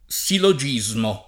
vai all'elenco alfabetico delle voci ingrandisci il carattere 100% rimpicciolisci il carattere stampa invia tramite posta elettronica codividi su Facebook sillogismo [ S illo J&@ mo ] (ant. silogismo [ S ilo J&@ mo ]) s. m.